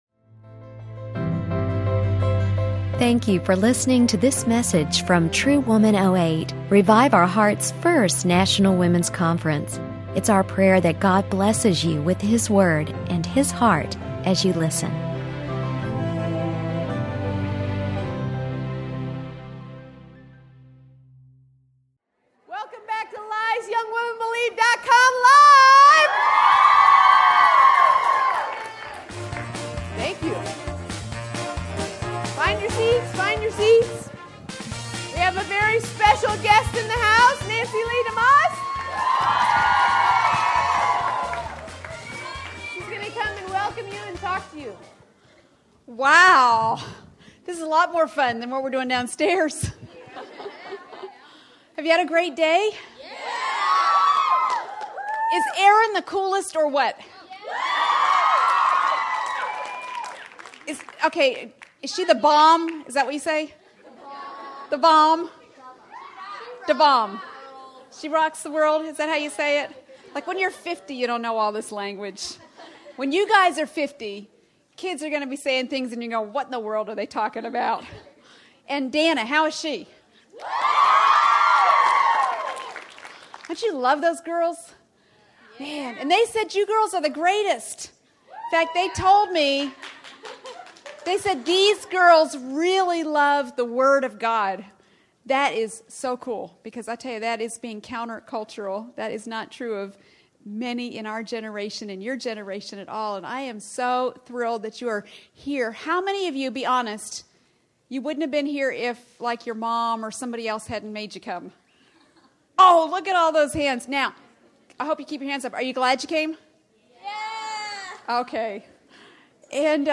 Teen Track—Lies About the Future | True Woman '08 | Events | Revive Our Hearts